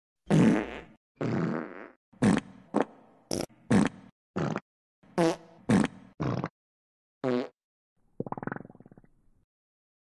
Play Sound De Peido - SoundBoardGuy
som-de-peidofarting-sound.mp3